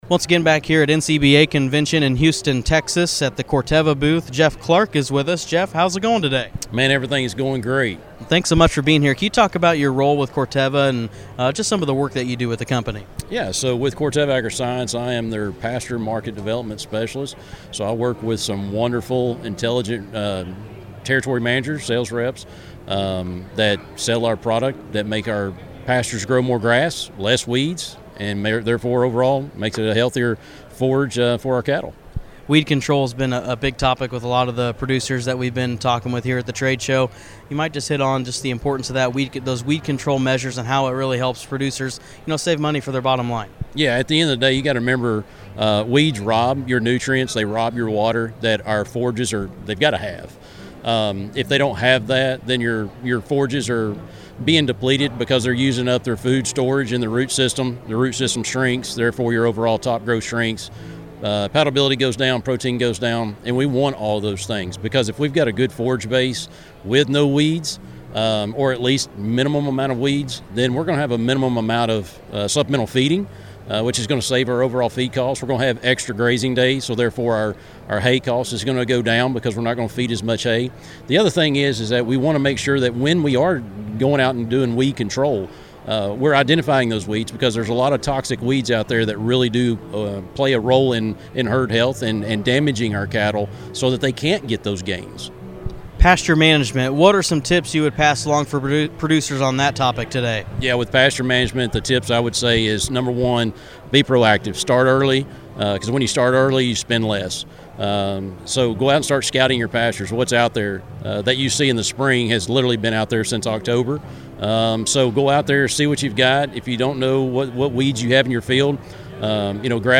NCBA Convention Audio